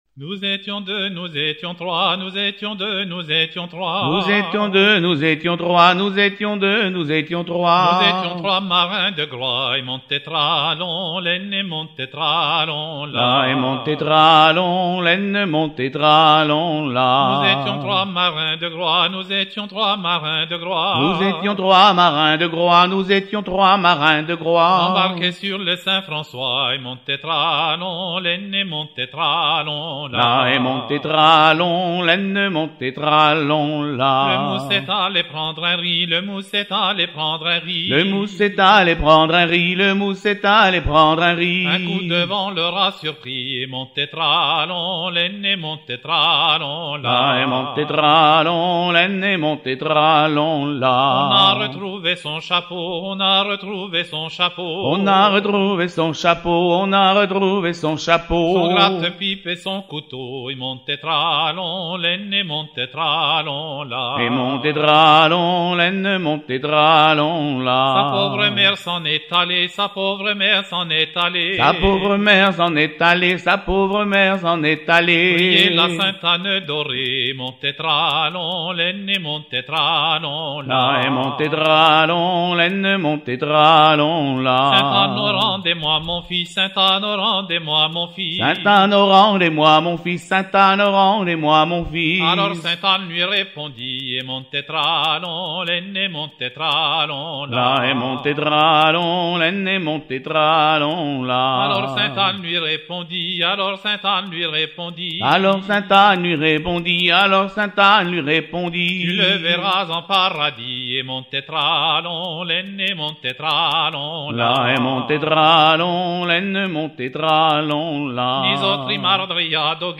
chanson soutient le rond à trois pas à Ouessant
danse : ronde à trois pas
chansons à danser